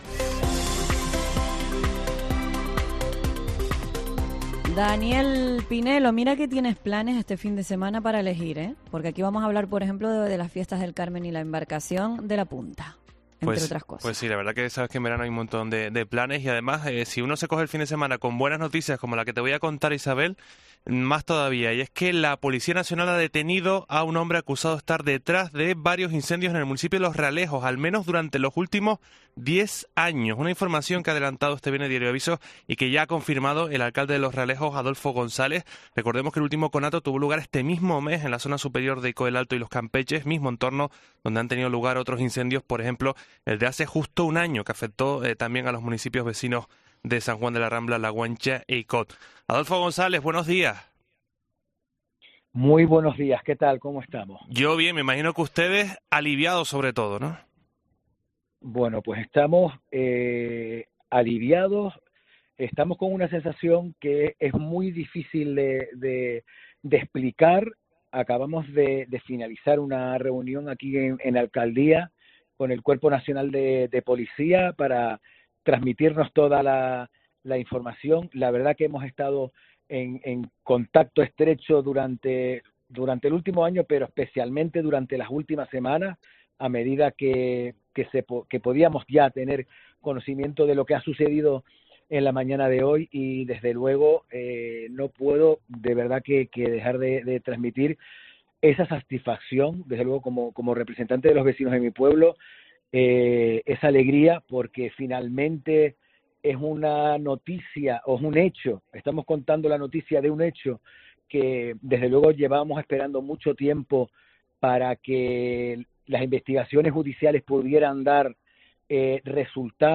El alcalde de Los Realejos, Adolfo González, contento por la detención del presunto pirómano